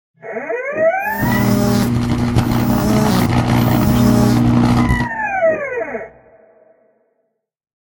shock-short.ogg.mp3